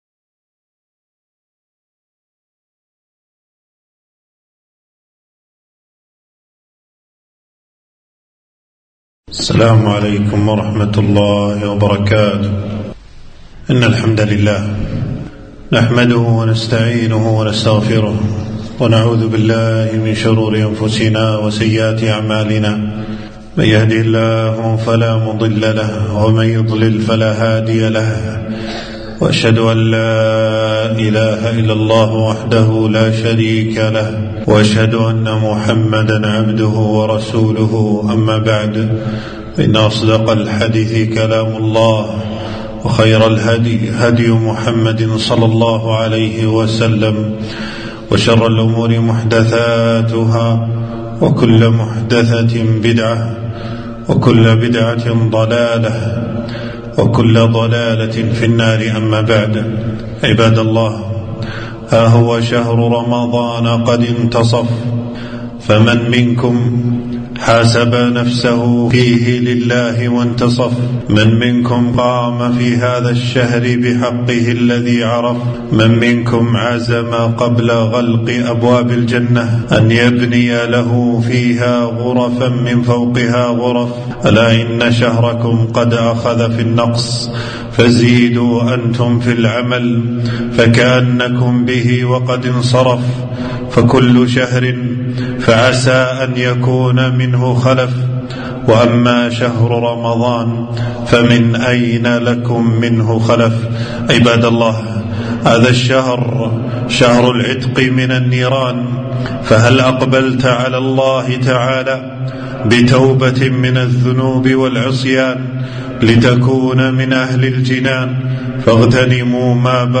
خطبة - فات النصف من رمضان فأدرك ما بقي